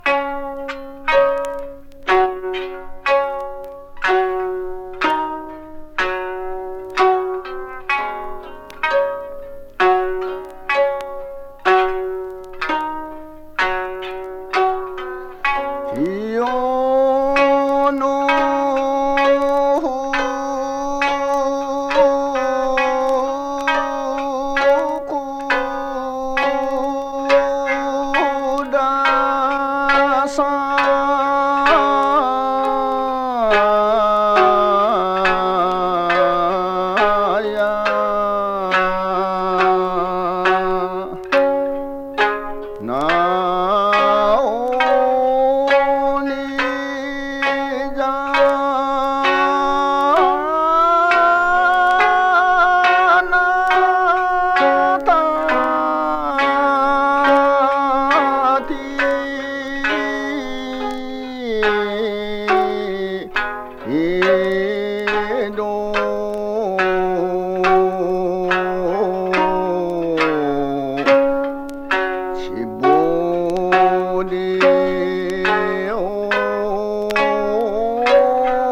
御前風 琉球古典音楽
迫力ありながらも、どこかリラックス効果があるような気がする「声」が魅力。